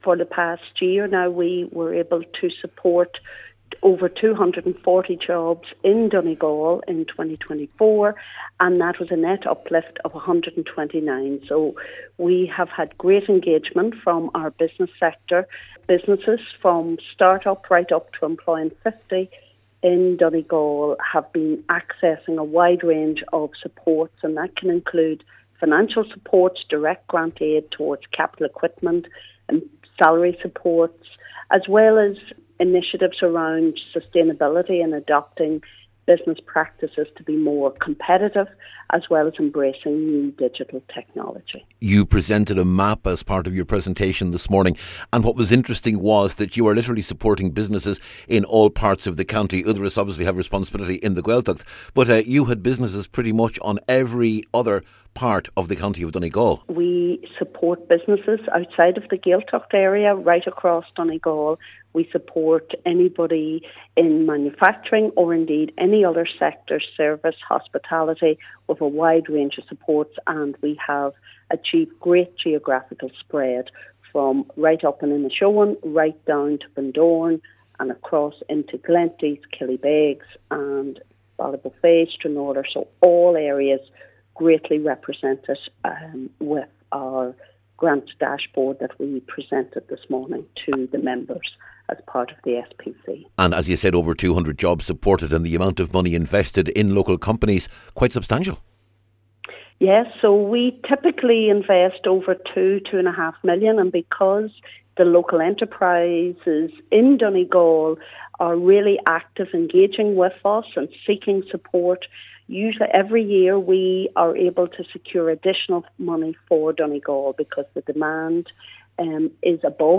A meeting of Donegal County Council’s SPC was told this morning that this is a county of small business.